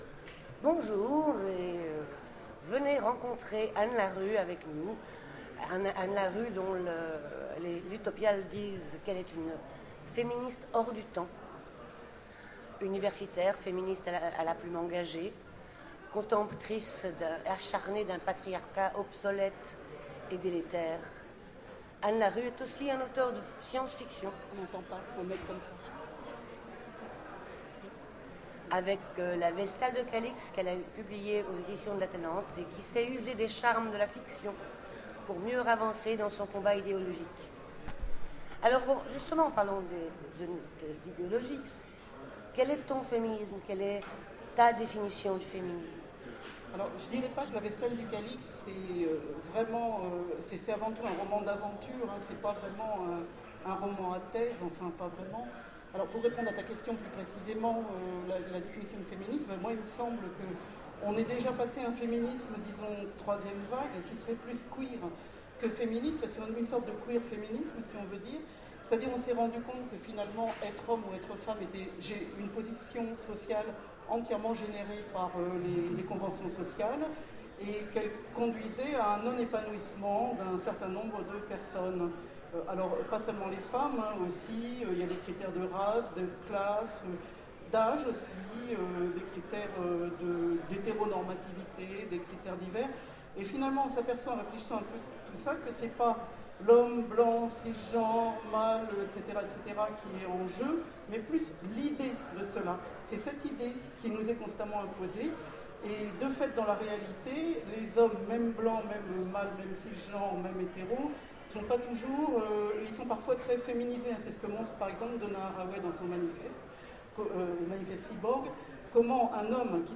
Rencontre avec un auteur Conférence